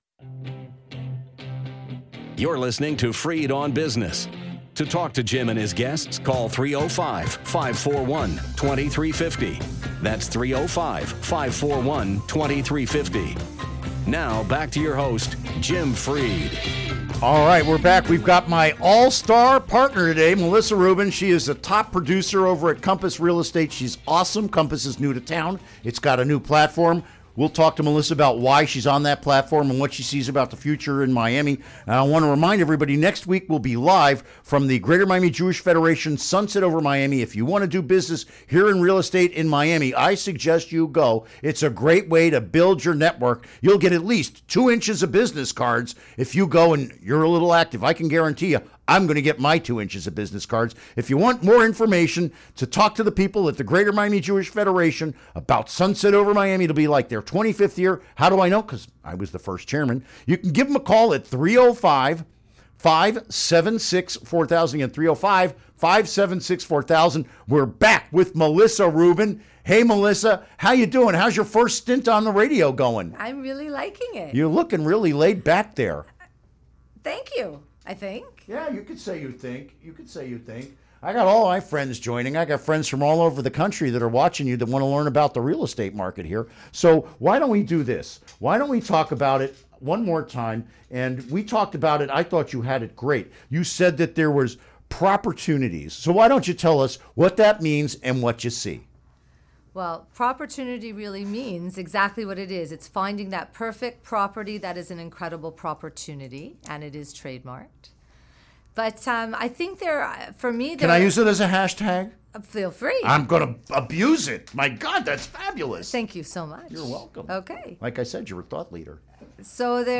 Interview Segment Episode 387: 09-22-16 Click here to download Part 1 (To download, right-click and select “Save Link As”.)